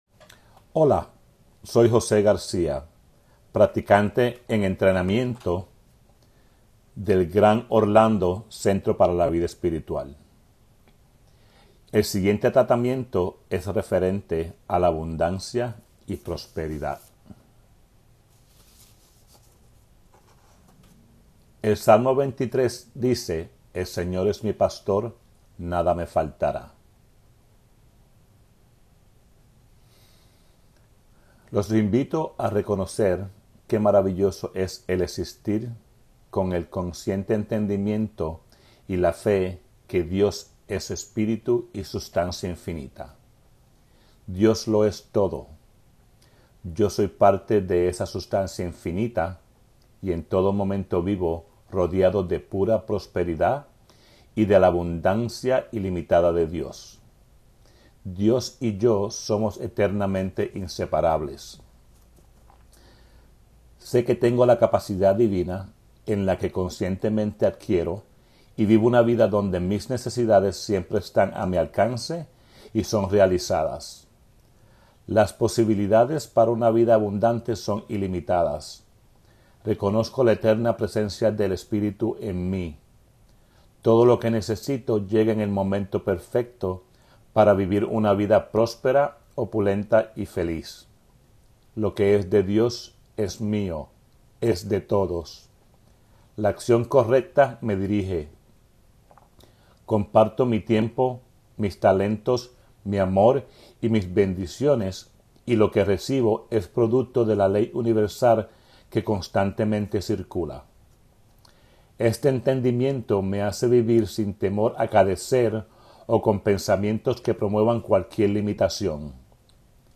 Recorded Prayers for Abundance